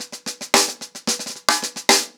TheQuest-110BPM.7.wav